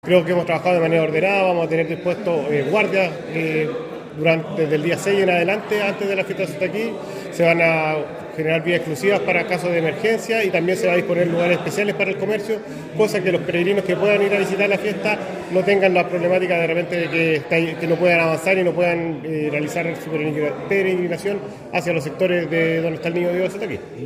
Por parte del municipio, el  alcalde de Ovalle, Héctor Vega Campusano, indicó que
Alcalde-de-Ovalle-Hector-Vega-Campusano.mp3